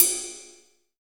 D2 RIDE-03.wav